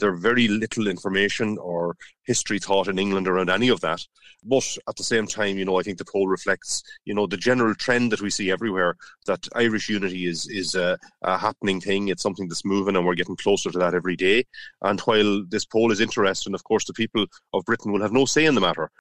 South Donegal Deputy Martin Kenny says Irish unity isn’t far away: